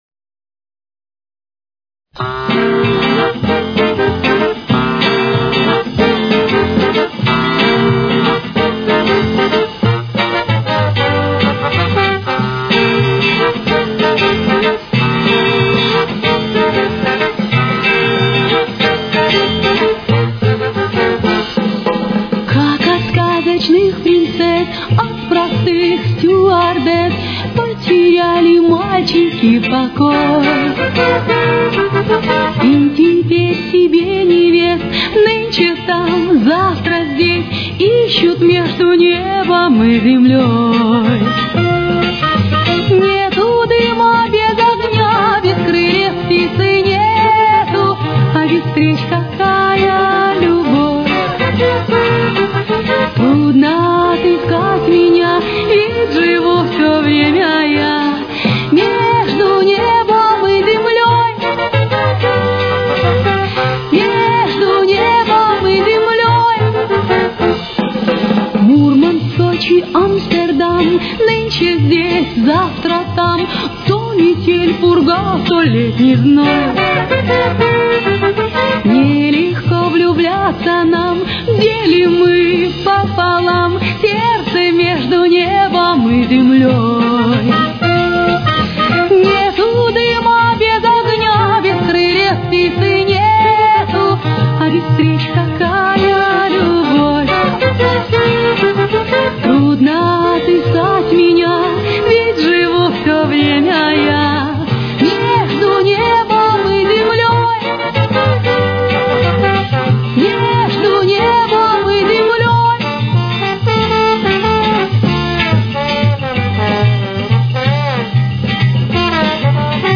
Темп: 188.